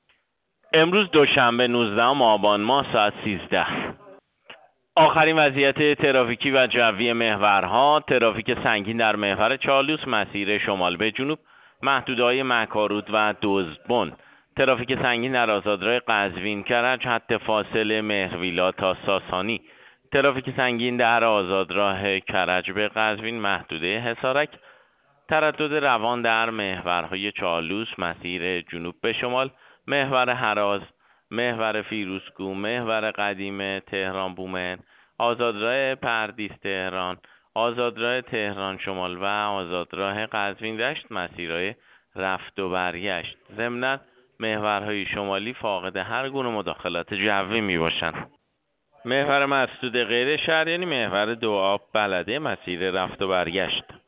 گزارش رادیو اینترنتی از آخرین وضعیت ترافیکی جاده‌ها ساعت ۱۳ نوزدهم آبان؛